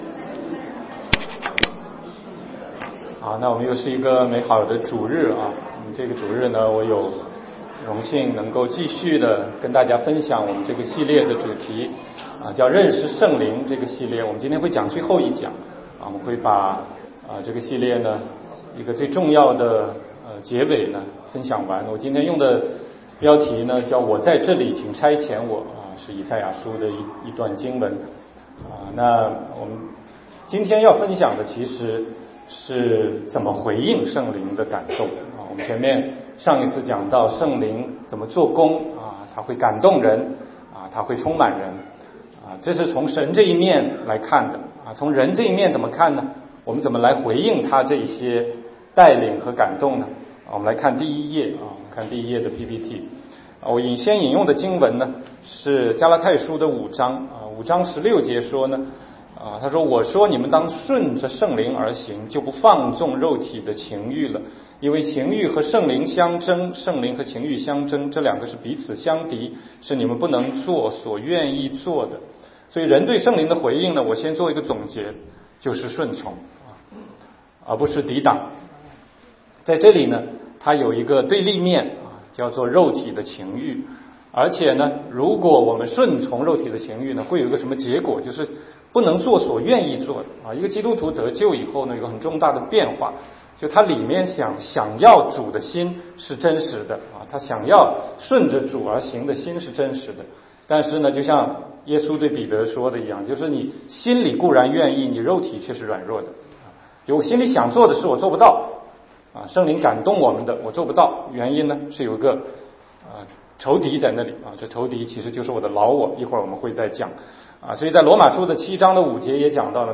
16街讲道录音 - 认识圣灵系列之七：我在这里，请差遣我
全中文查经